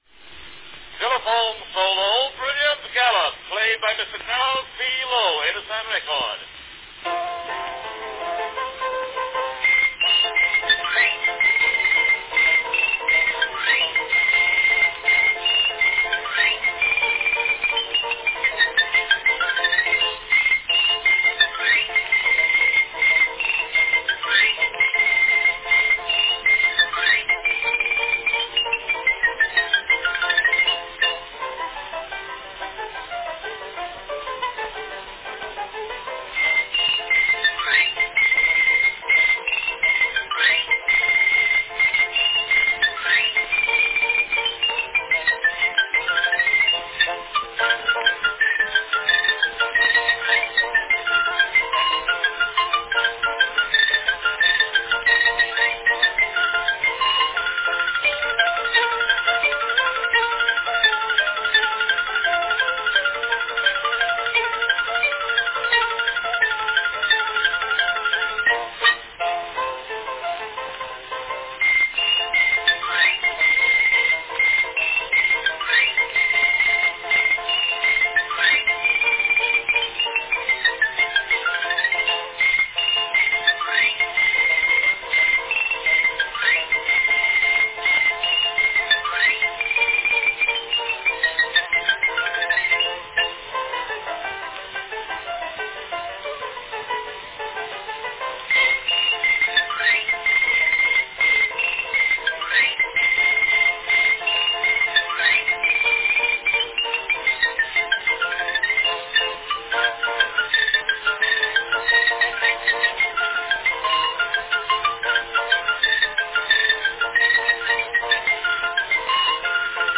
a high-energy xylophone recording
Category Xylophone solo
fast-paced 1900 remake